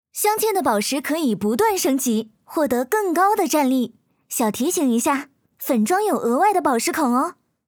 snd_ui_baoshixiangqian.wav